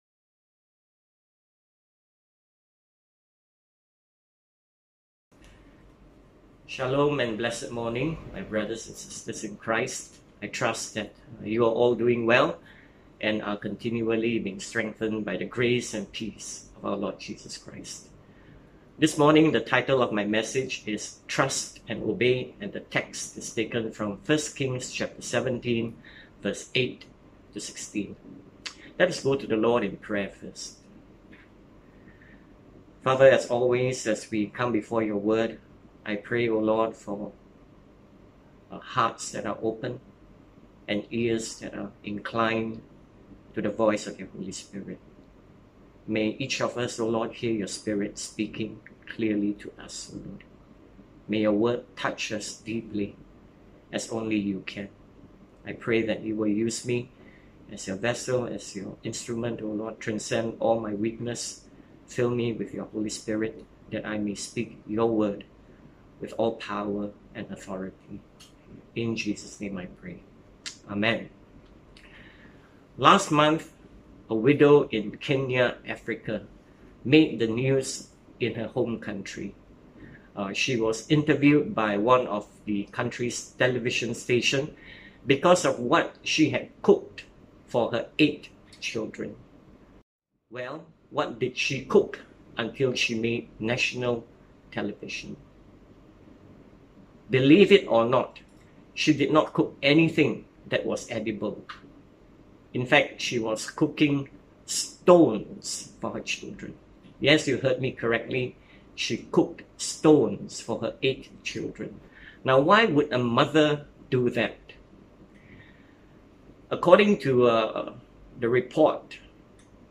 Bible Text: 1Kings 17:8-16 | Preacher